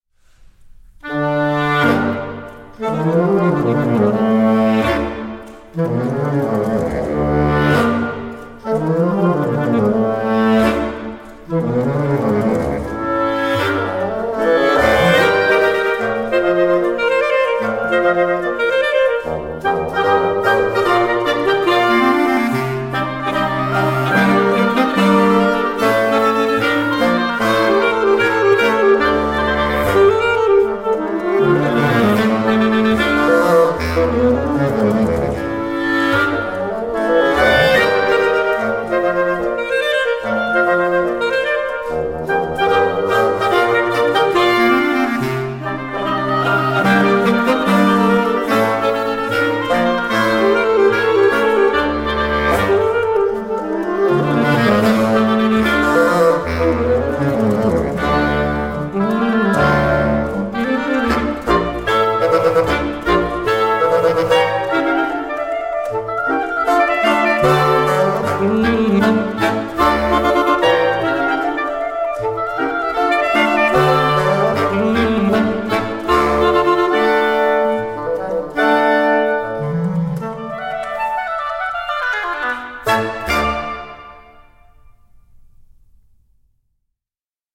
Live-Mitschnitte aus dem Konzertsaal